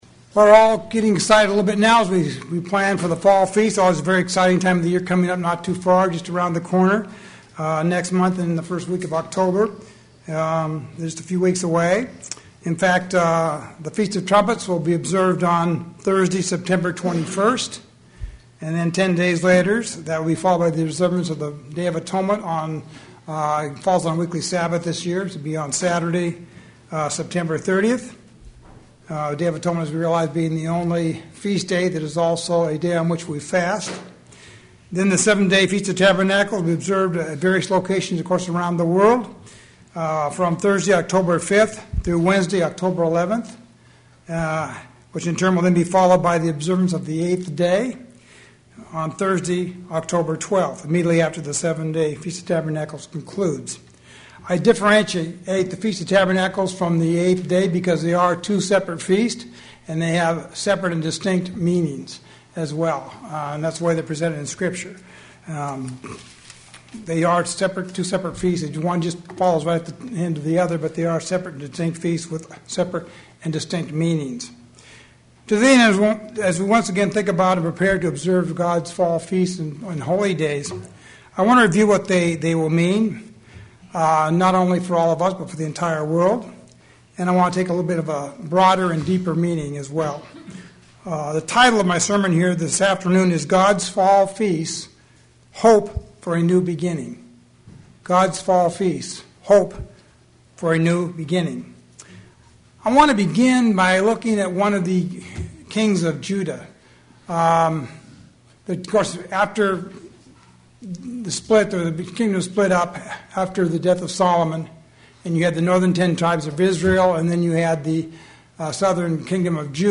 Today, I will tell you that they give us hope for a new beginning. sermon Transcript This transcript was generated by AI and may contain errors.